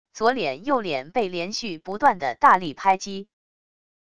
左脸右脸被连续不断的大力拍击wav音频